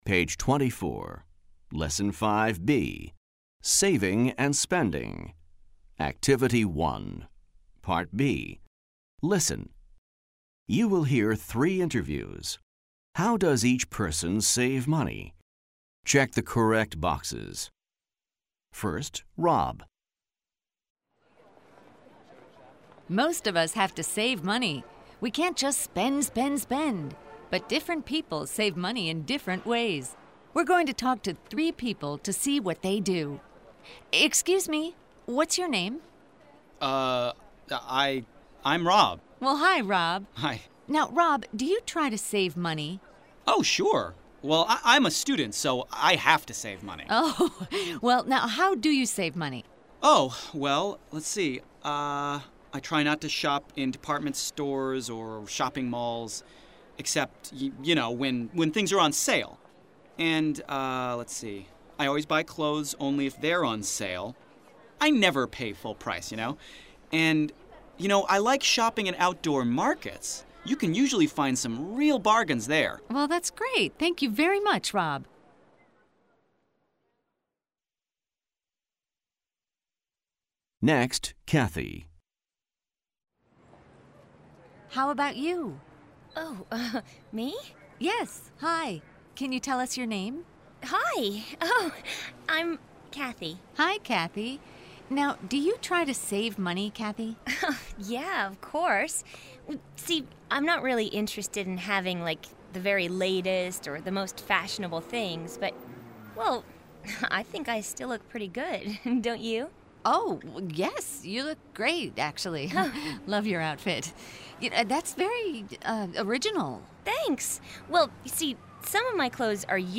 American English
New recordings offer authentic listening experiences in a variety of genres, including conversations, interviews, and radio and TV shows.